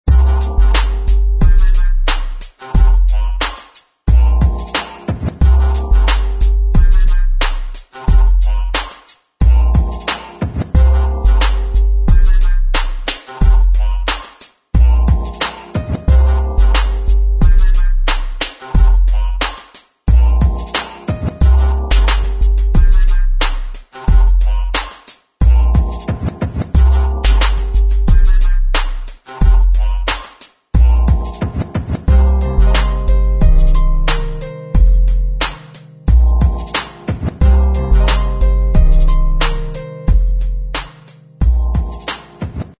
This beat will rock you into next week with the low bass.